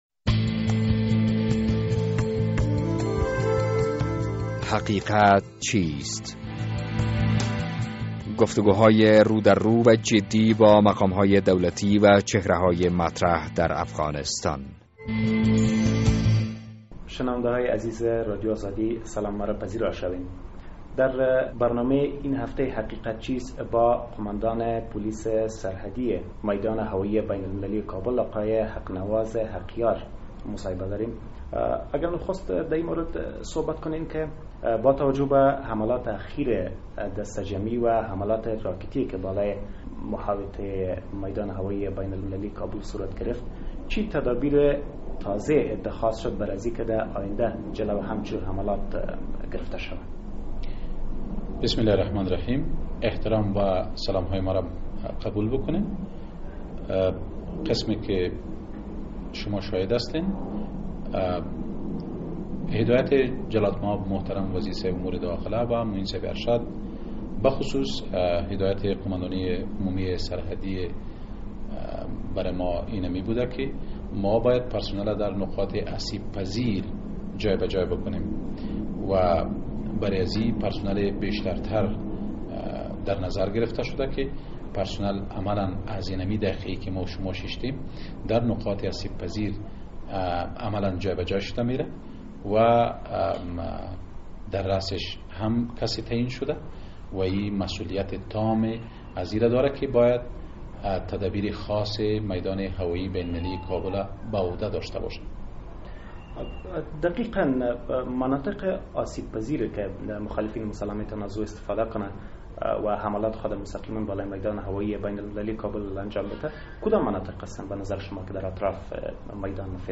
در این برنامه حقیقت چیست با حق نواز حقیار قوماندان پولیس سرحدی میدان هوایی بین المللی کابل گفتگو کرده ایم...